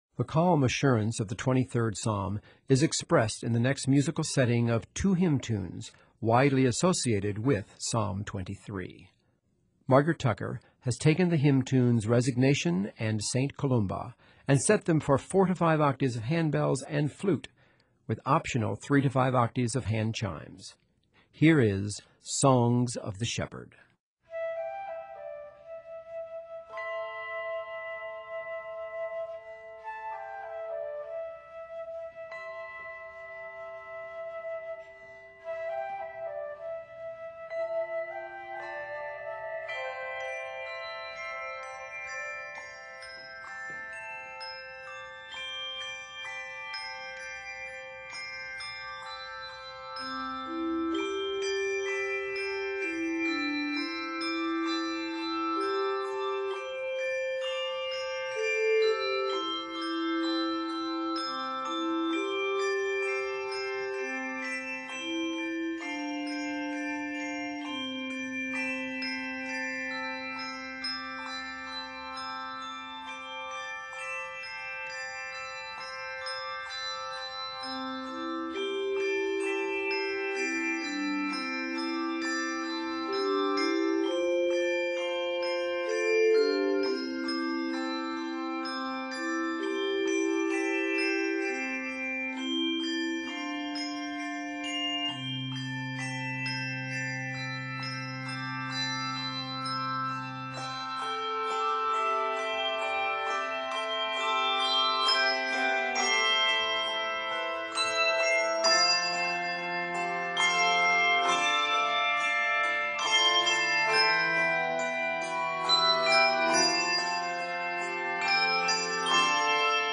hymn tunes